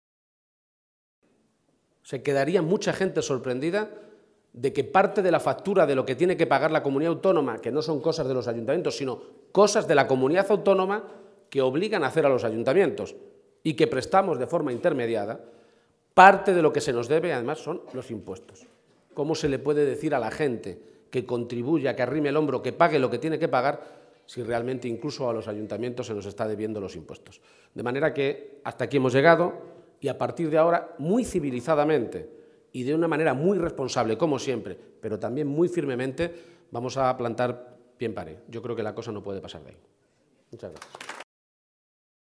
Page-reunion_alcaldes_psoe.mp3